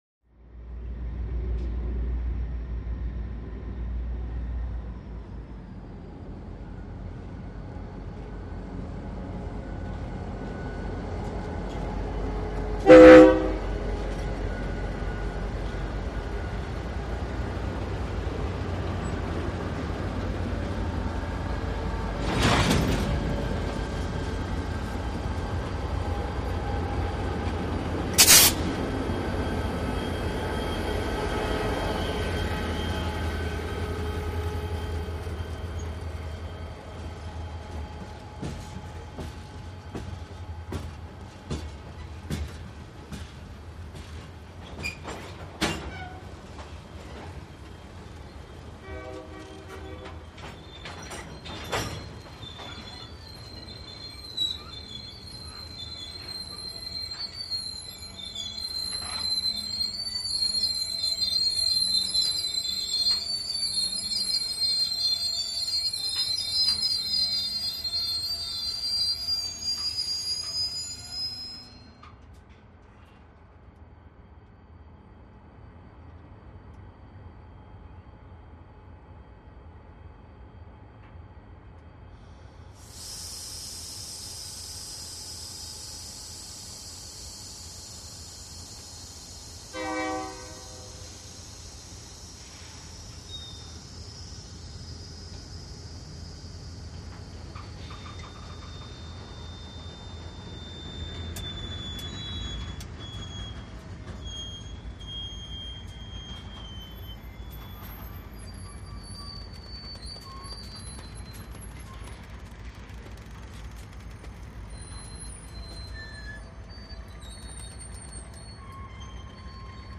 Freight Train Close Horn Blow And Air Release. By Left To Right Very Slow With Brake Squeals Metal Stress Creaks And Squeaks.